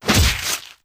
Melee Weapon Attack 30.wav